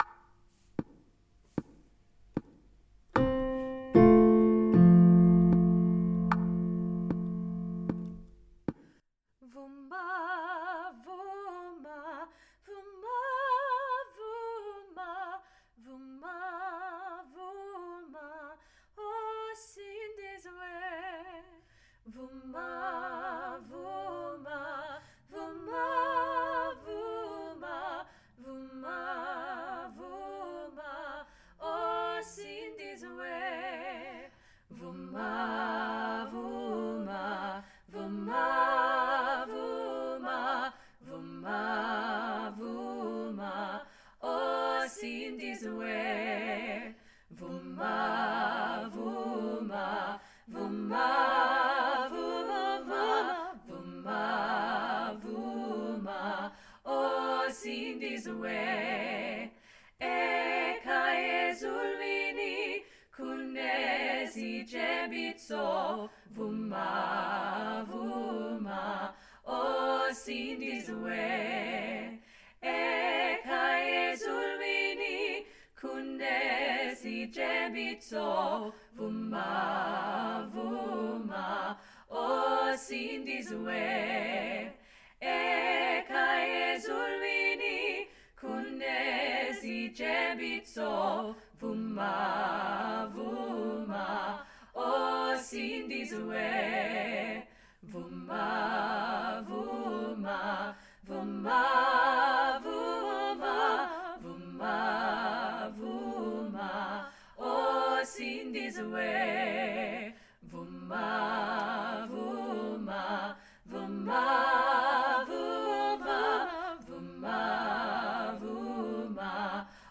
SATB Vuma - Three Valleys Gospel Choir
SATB Vuma
SATB-Vuma.mp3